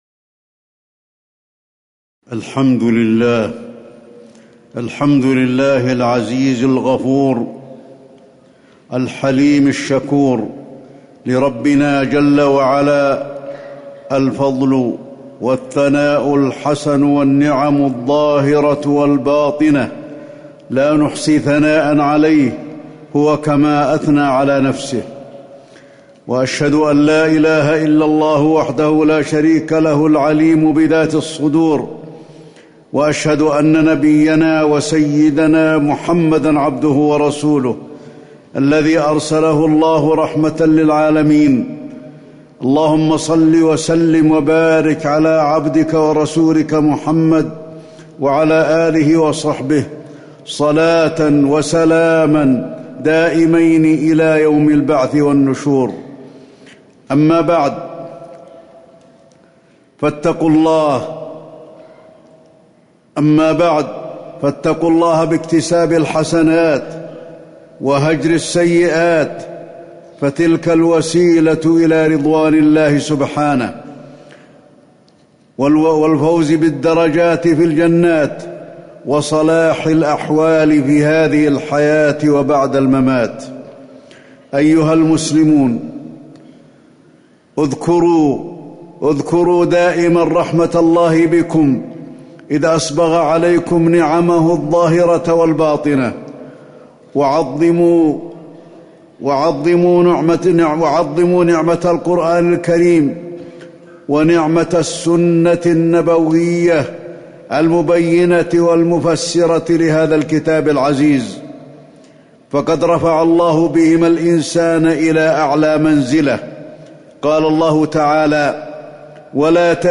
تاريخ النشر ١٧ جمادى الآخرة ١٤٤٠ هـ المكان: المسجد النبوي الشيخ: فضيلة الشيخ د. علي بن عبدالرحمن الحذيفي فضيلة الشيخ د. علي بن عبدالرحمن الحذيفي التوكل على الله